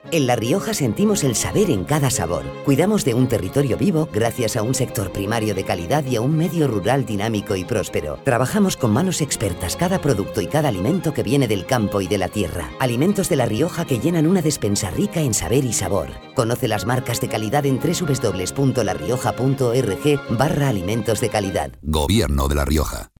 Cuñas radiofónicas
Cuña